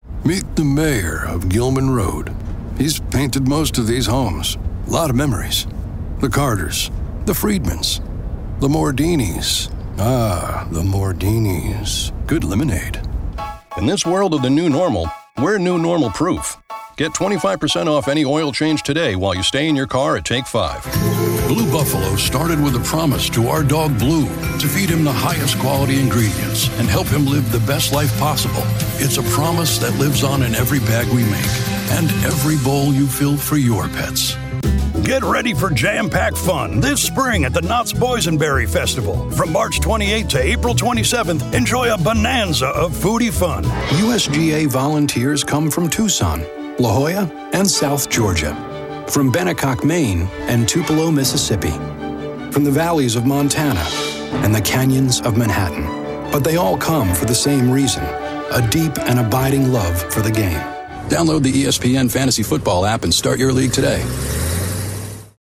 Commercial Reel